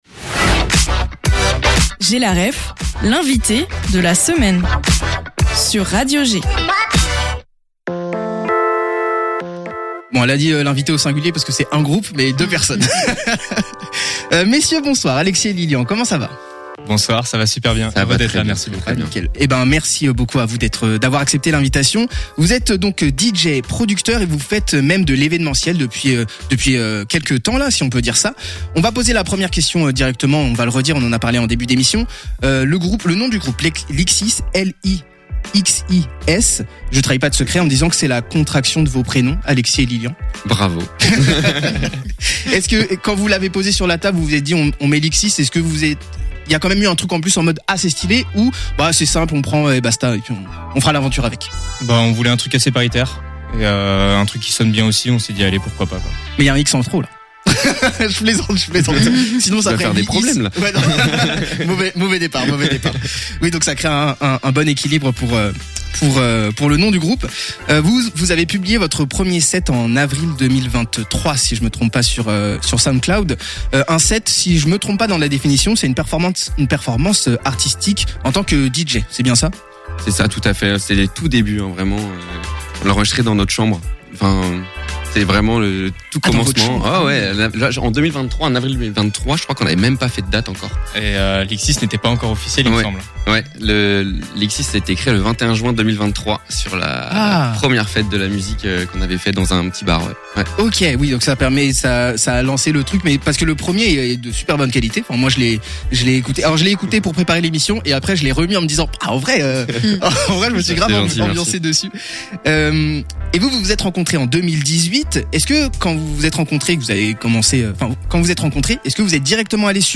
Interview Lixis Music - G!